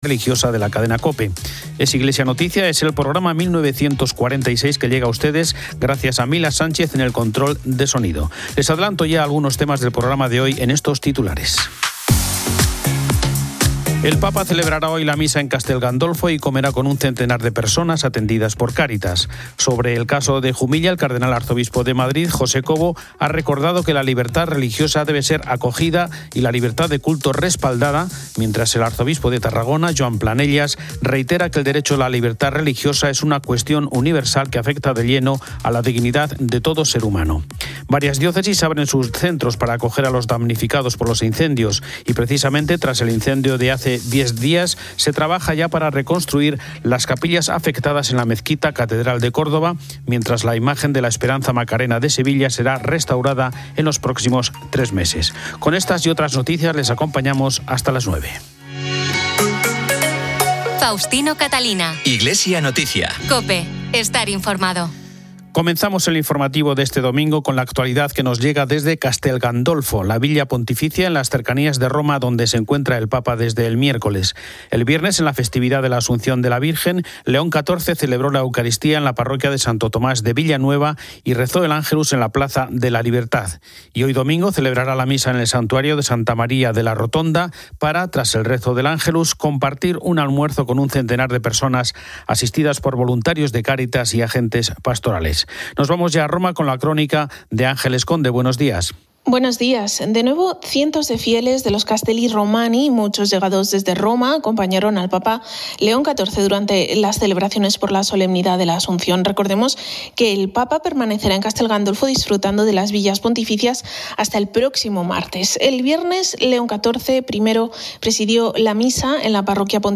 Todo el informativo "Iglesia Noticia" detalla las actividades del Papa en Castel Gandolfo, donde celebró misa y almorzó con personas atendidas por Cáritas. El Cardenal Cobo y el Arzobispo Planellas se pronunciaron sobre la libertad religiosa y la migración, enfatizando la acogida y el respaldo a la libertad de culto, y la importancia de la presencia religiosa en el espacio público. Varias diócesis abrieron sus centros para damnificados por incendios.